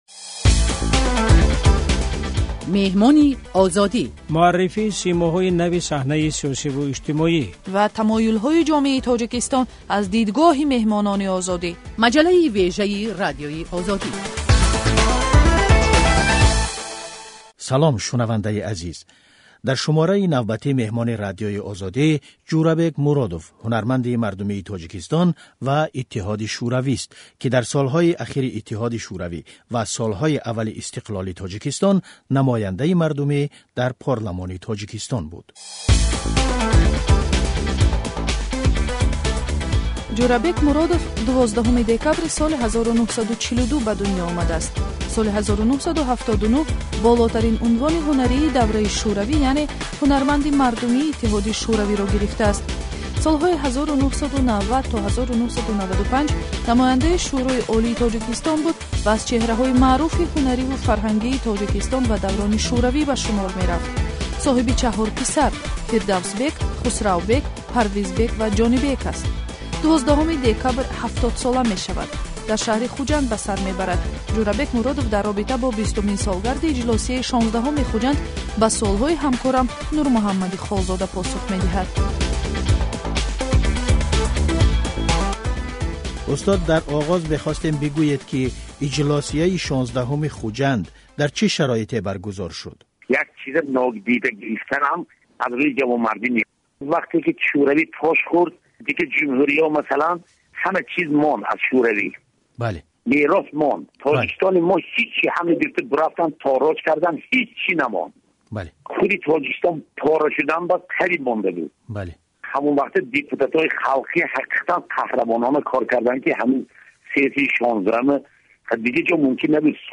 Сӯҳбати хабарнигори Озодӣ бо Ҷӯрабек Муродов, ҳунарманди мардумии Иттиҳоди Шуравӣ ва яке аз иштирокдорони Иҷлосияи Хуҷанд.